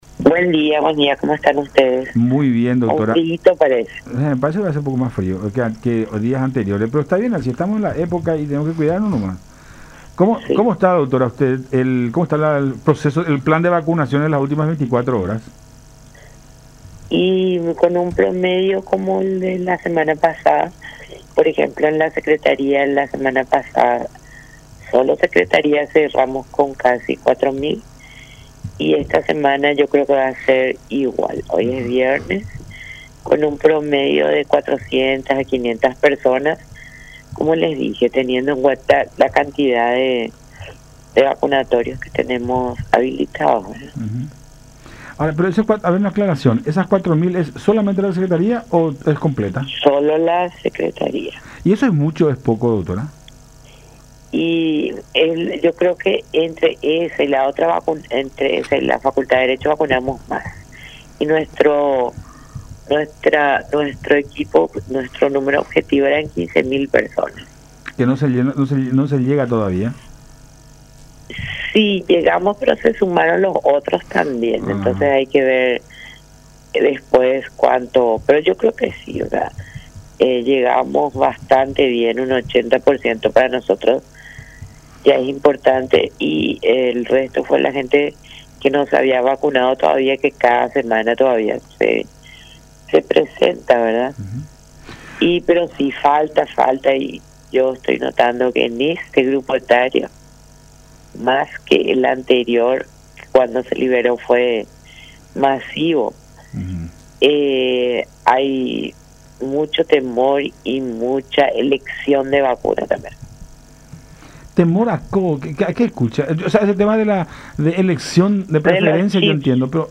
Yo les respondo que vamos a morir antes si no nos vacunamos”, expresó la profesional en conversación con Cada Mañana por La Unión.